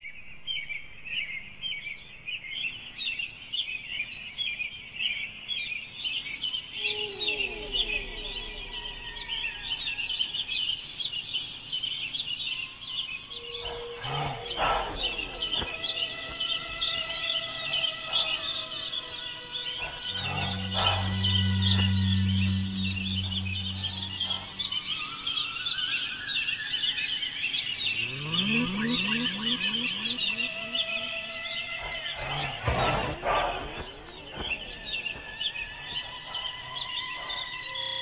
Strumentale